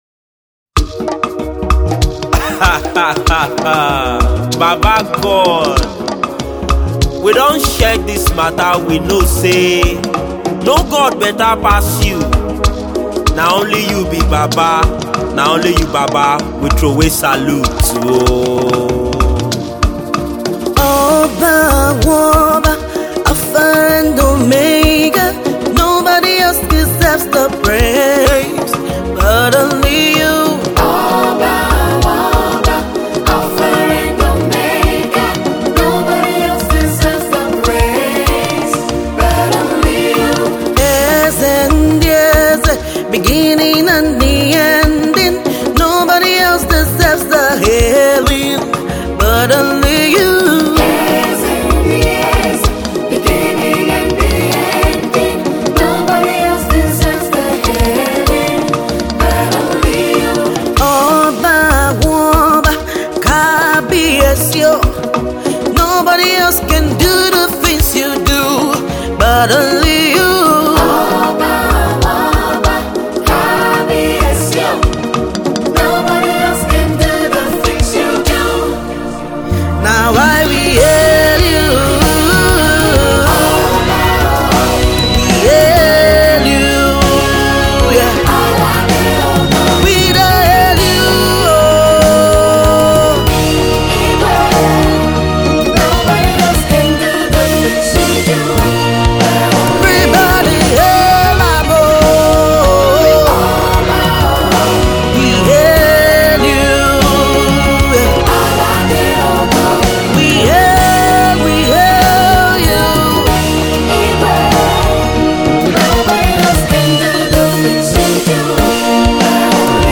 a powerful new yoruba song